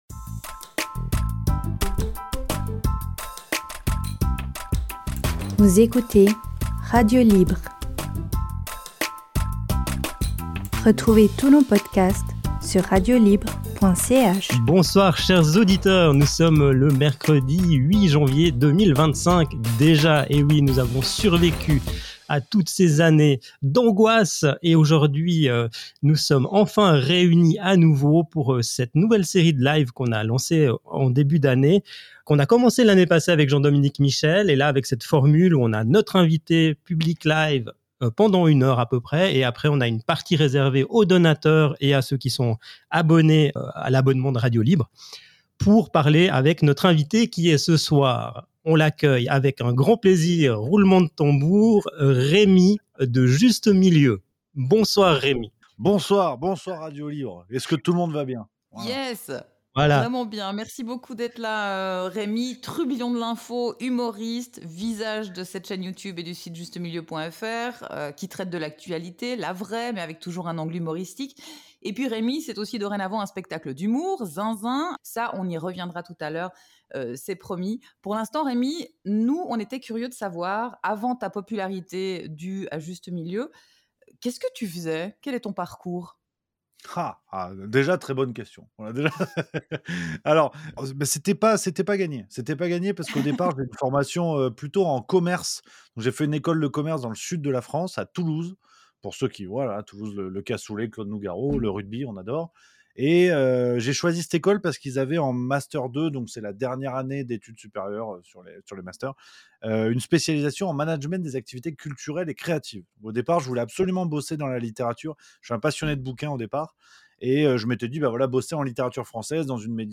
Cet entretien fait partie de notre série d’émissions en direct du mercredi 19h, suivies de discussions privées avec nos invités.